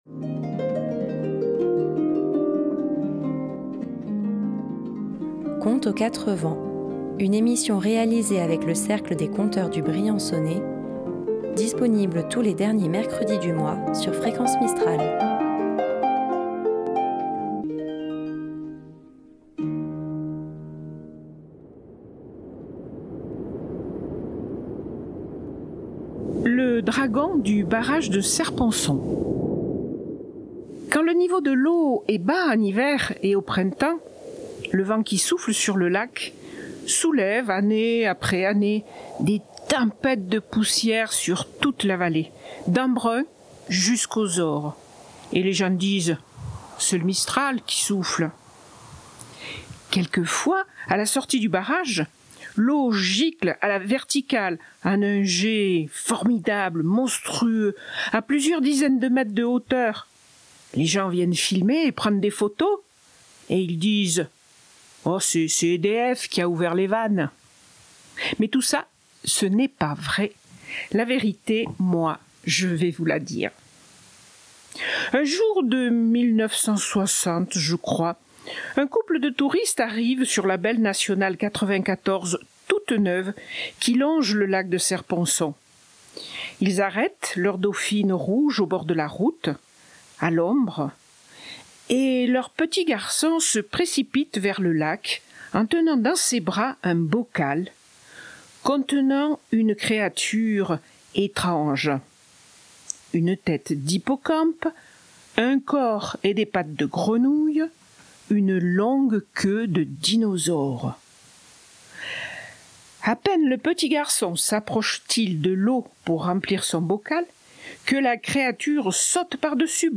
Tous les derniers mercredi du mois, à 18h, retrouvez le Cercle des conteurs du Briançonnais pour une balade rêveuse.